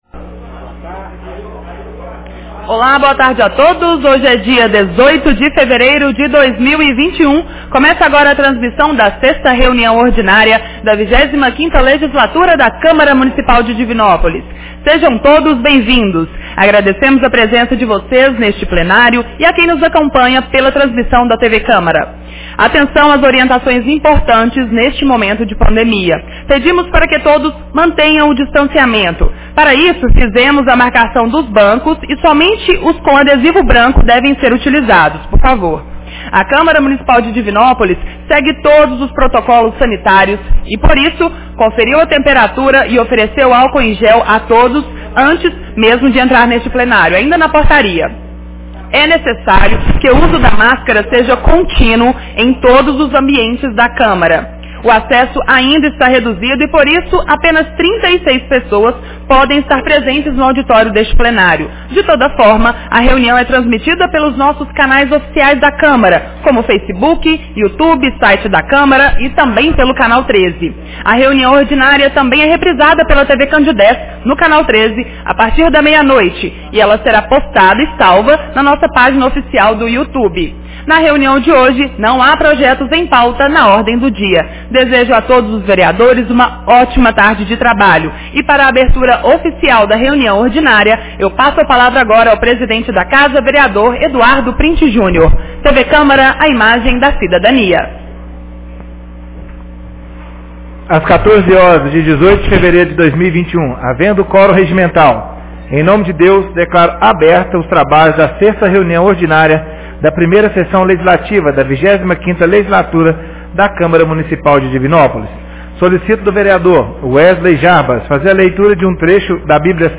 Reunião Ordinária 06 de 18 fevereiro 2021